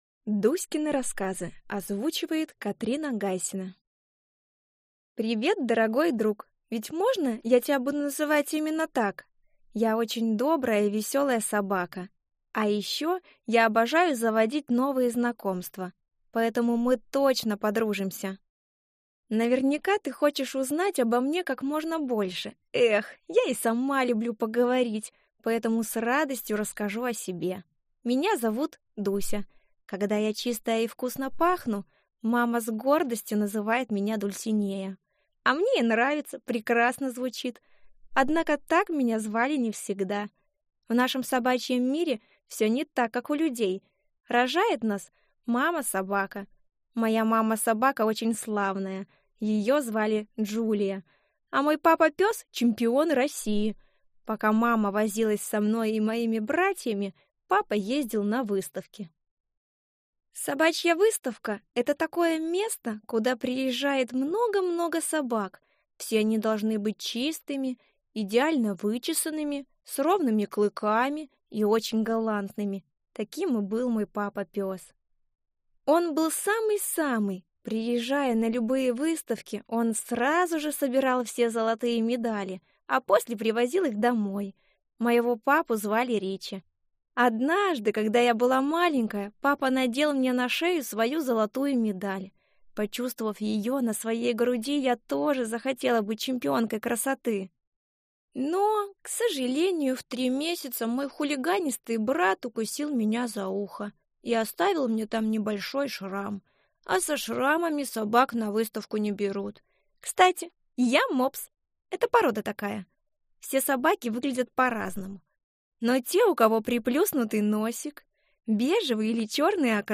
Аудиокнига Дуськины рассказы | Библиотека аудиокниг